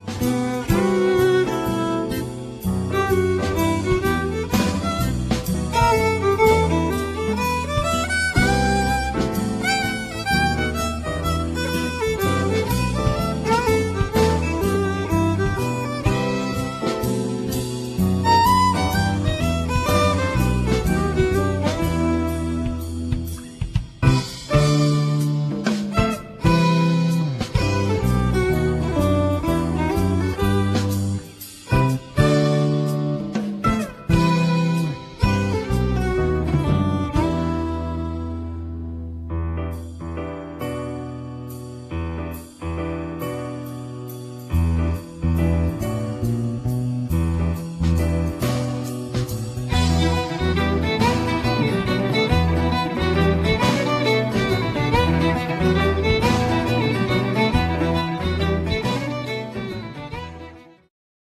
instrumentalny
gitara basowa
skrzypce
perkusja, instr. perkusyjne
bouzuki, cittern, gitara akustyczna